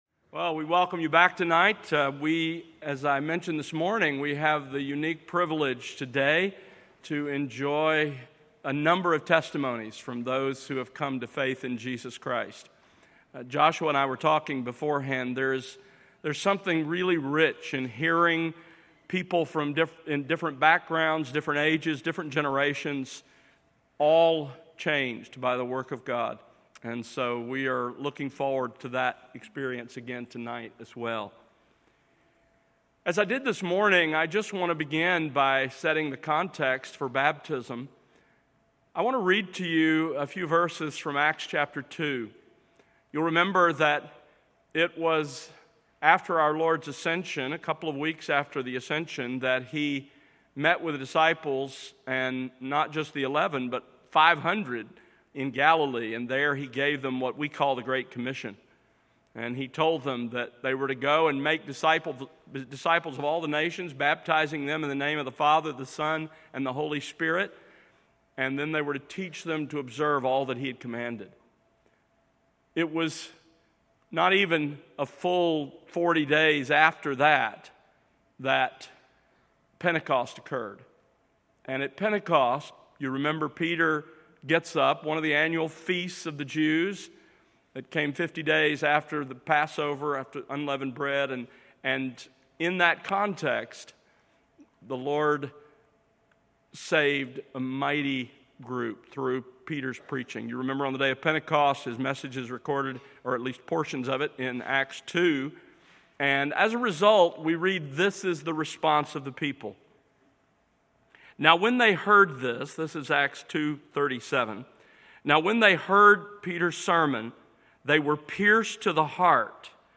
Evening Baptisms